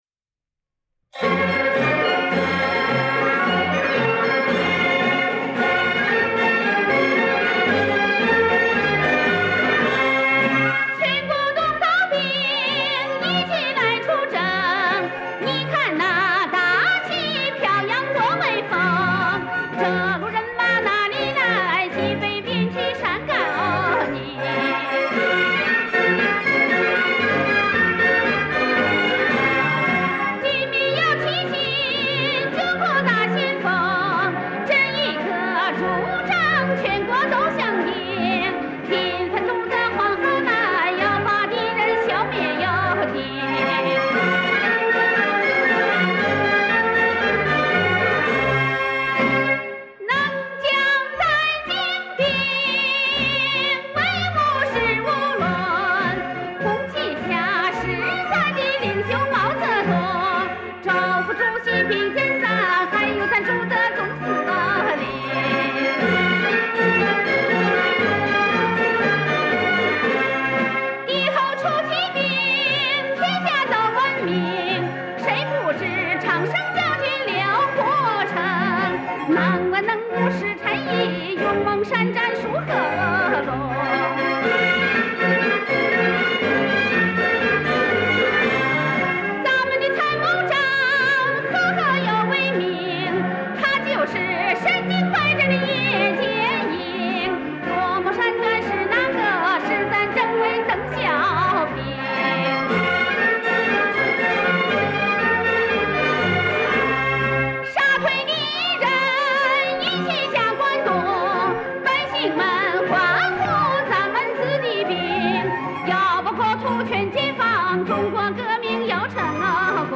陕北民歌